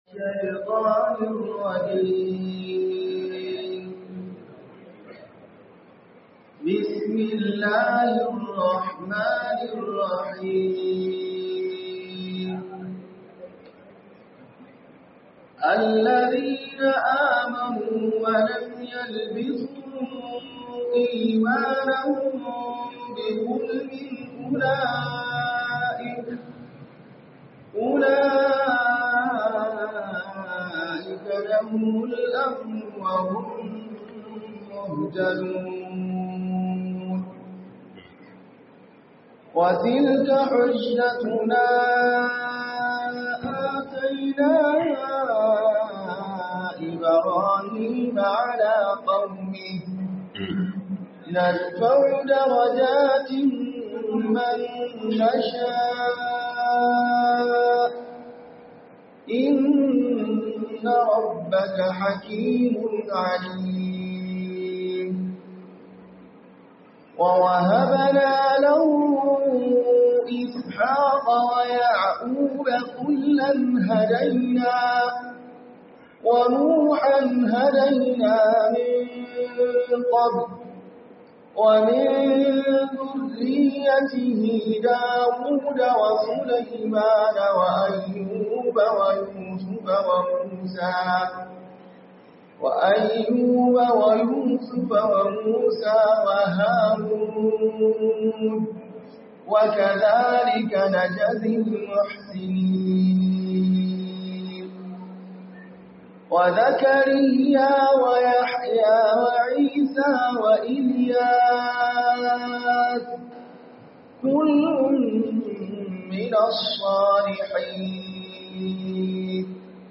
MUHADARA A NIAMEY 04